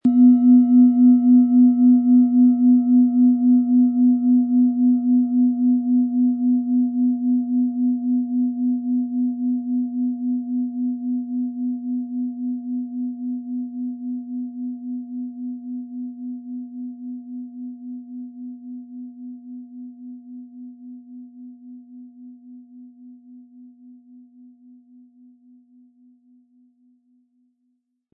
Planetenton 1
• Sanfter, tragender Klang: Ideal für die tiefgehende Klangarbeit, Meditation und die Auseinandersetzung mit dem Unbewussten.
Im Sound-Player - Jetzt reinhören können Sie den Original-Ton genau dieser Schale anhören.
SchalenformOrissa
MaterialBronze